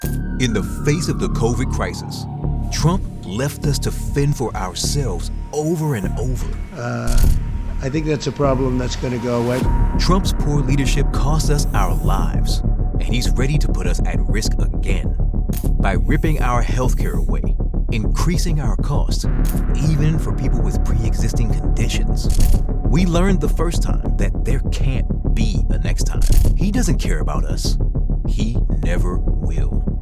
Politische Anzeigen
Meine Stimme ist hymnisch, geerdet und unverkennbar echt – eine Mischung aus Textur, Kraft und Seele, die das Publikum berührt und Ihre Botschaft hervorhebt.
Sennheiser 416, Neumann U87, TLM 103